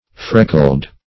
Freckled \Frec"kled\ (fr[e^]k"k'ld), a.